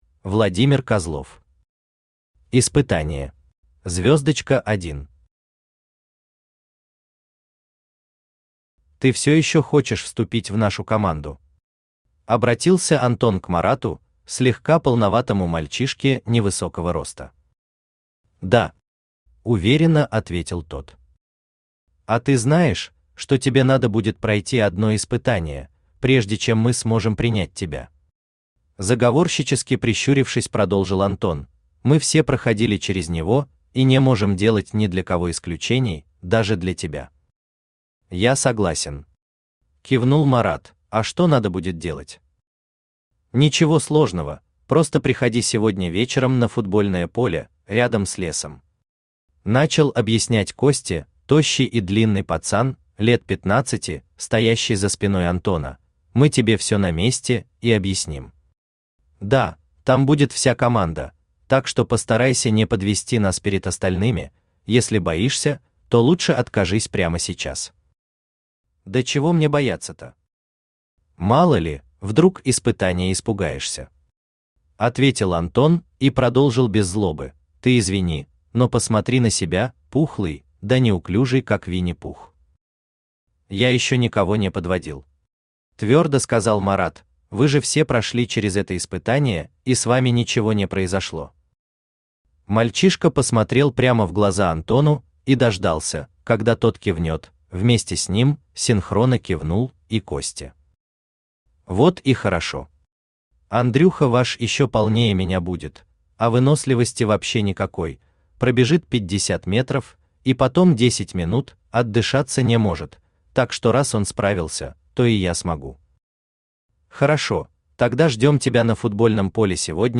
Аудиокнига Испытание | Библиотека аудиокниг
Aудиокнига Испытание Автор Владимир Олегович Козлов Читает аудиокнигу Авточтец ЛитРес.